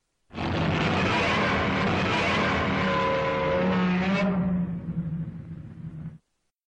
40 Godzilla - Roar